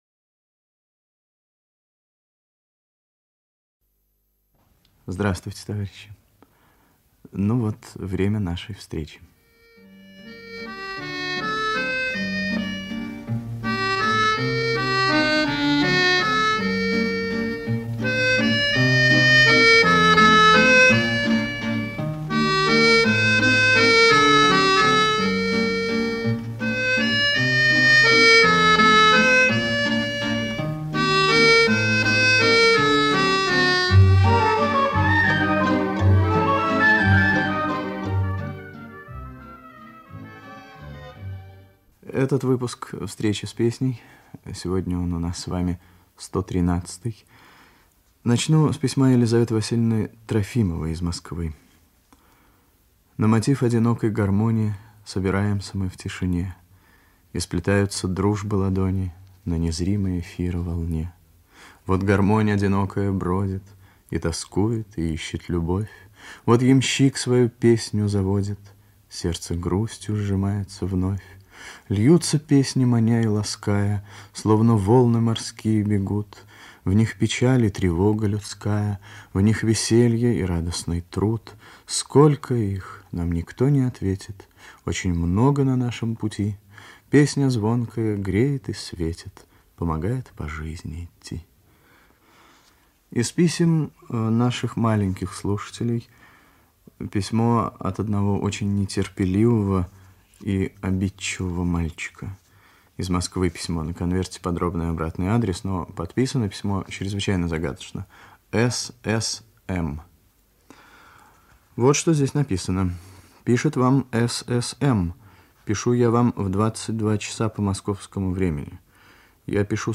Радиопередача "Встреча с песней" Выпуск 113
Ведущий - автор, Виктор Татарский.